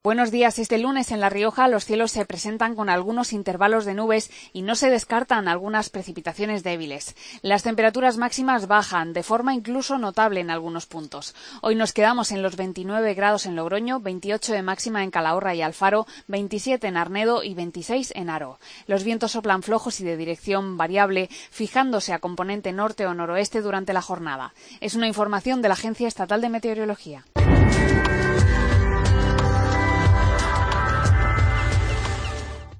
Pronóstico del Tiempo, 27 de julio 2015